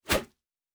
pgs/Assets/Audio/Fantasy Interface Sounds/Whoosh 01.wav at master
Whoosh 01.wav